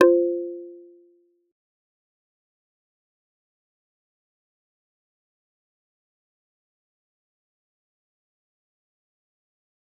G_Kalimba-F4-mf.wav